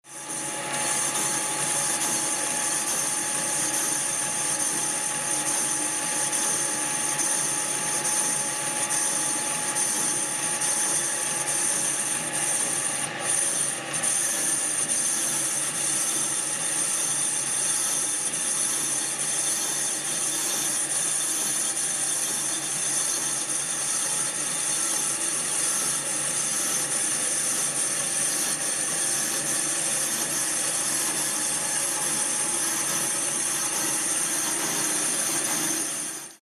第10回都営バス「ドラム研磨音」
第10回 都営バス「ドラム研磨音」 自動車工場で行われる整備作業の音。車体からブレーキドラムをホイールごと取り外し、専用の機材で回転させながら研磨します。ドラム内面の凹凸を修正し、万全の状態を保ちます。